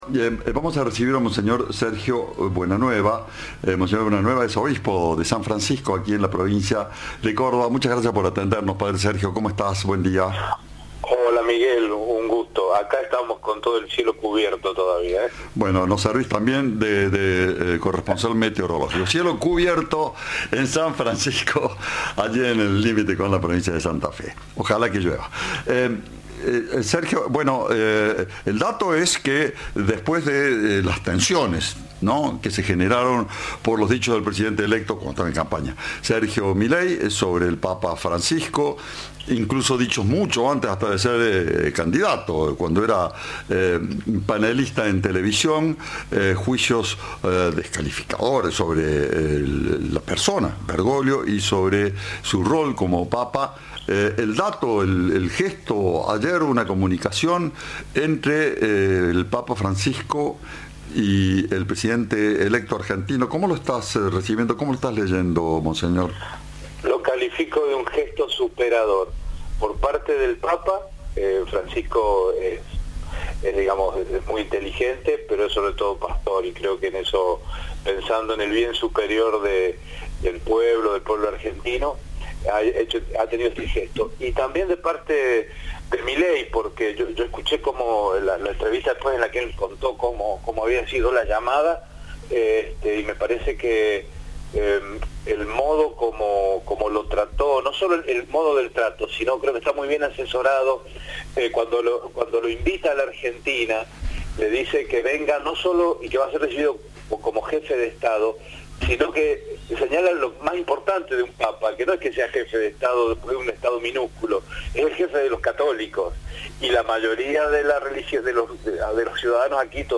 Monseñor Sergio Buenanueva, obispo de San Francisco habló con Cadena 3 sobre la conversación entre el presidente electo Javier Milei y el Papa Francisco, a quien invitó a venir a la Argentina.
Entrevista